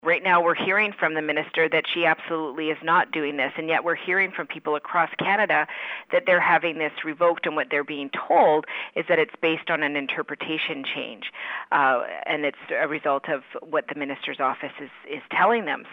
Blaney says the biggest concern is the Minister of Health is telling a different story: